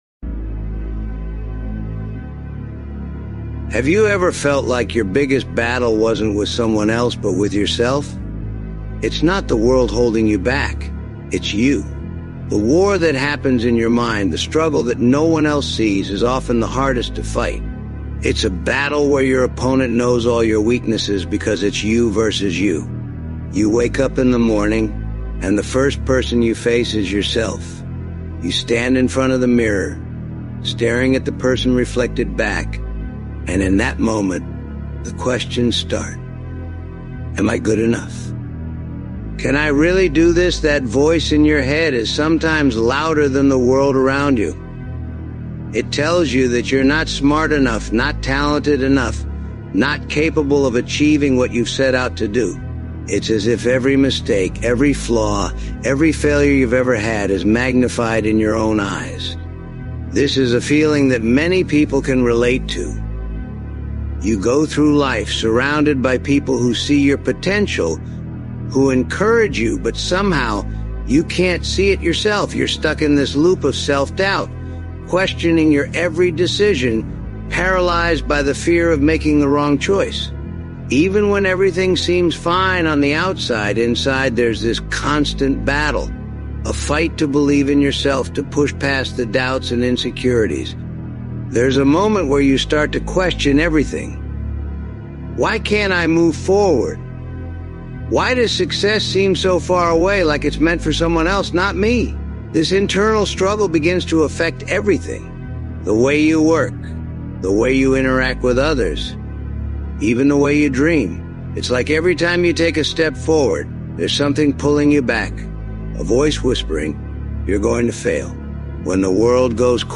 Transform Your Fears Into Power | Motivational Speech